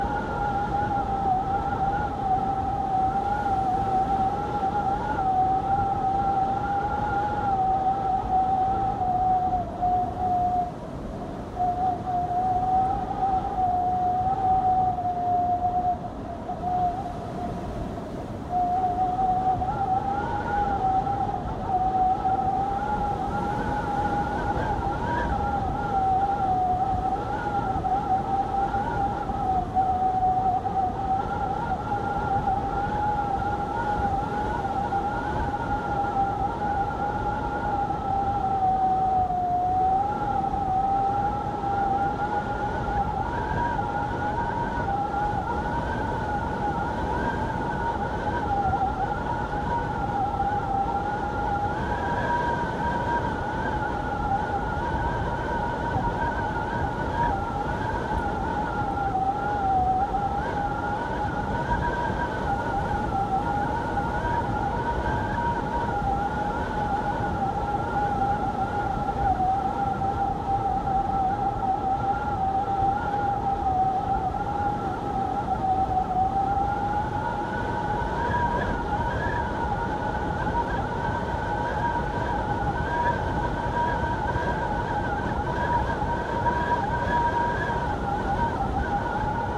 Звуки плохой погоды
Звук ледяного зимнего ветра в снежную бурю